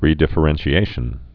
(rēdĭf-ə-rĕnshē-āshən)